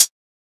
edm-hihat-56.wav